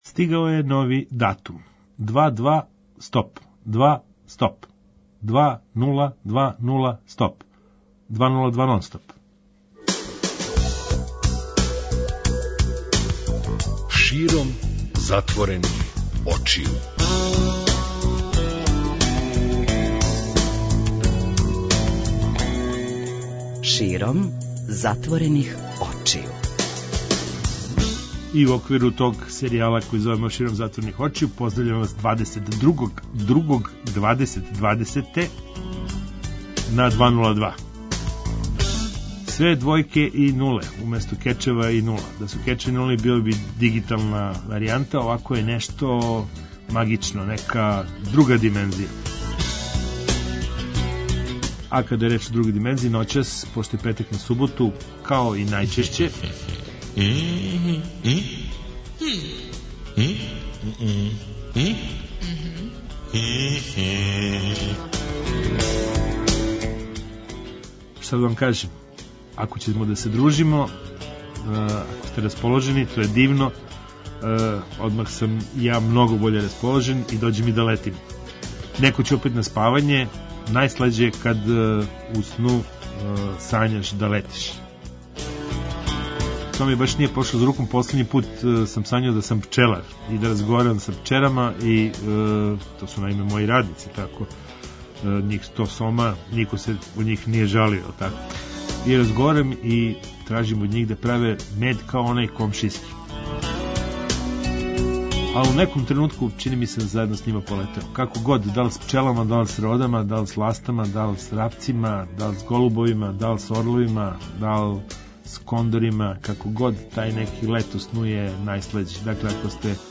Зато ћемо и ноћас да причамо о неколико тема уз непрекидан контакт са публиком.